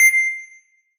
ボタン音
効果音 ＞ ボタン音